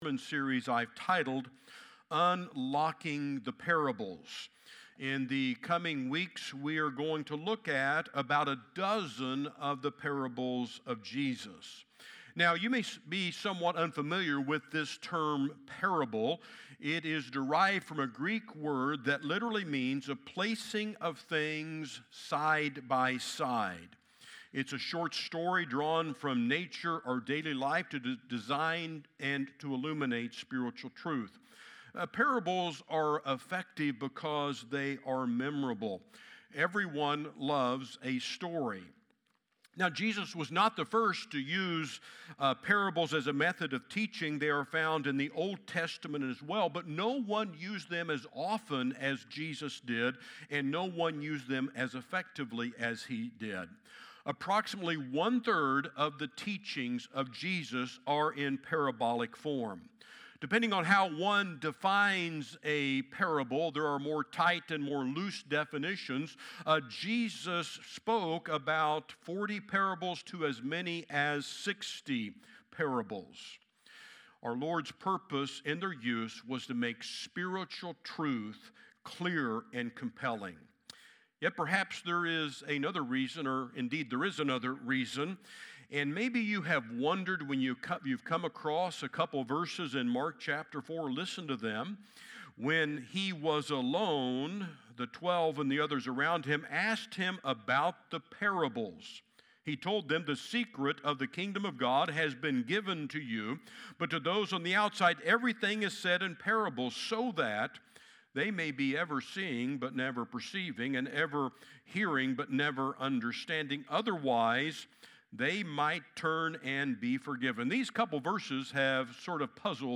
sermon on The Parable of the Good Samaritan from Luke 10:25-37.